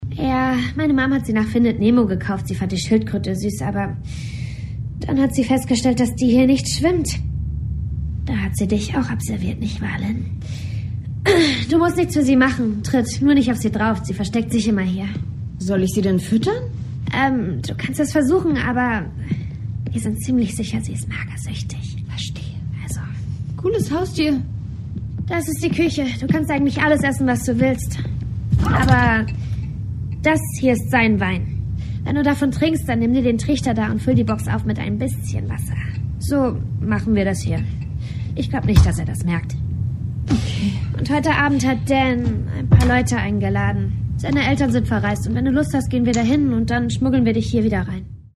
Sprecherin, Synchronsprecherin, Schauspielerin, Sängerin